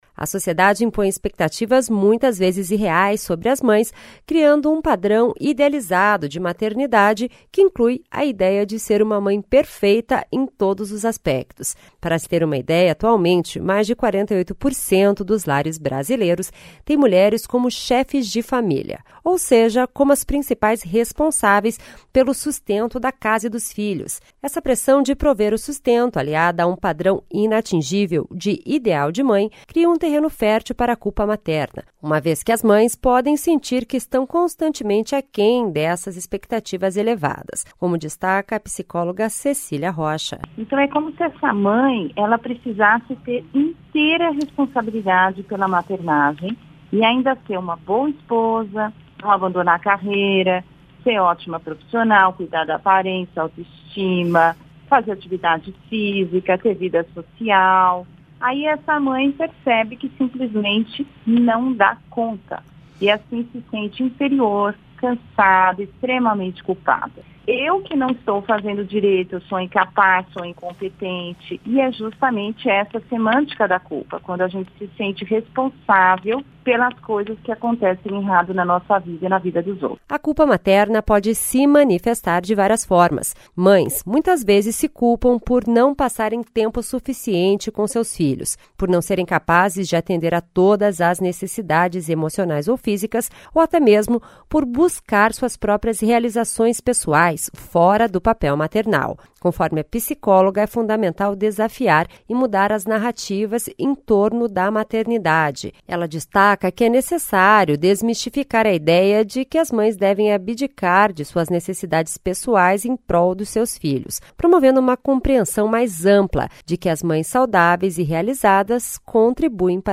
Especialista fala sobre a importância de desconstruir mitos em torno da maternidade perfeita, que gera sentimento de culpa e ansiedade.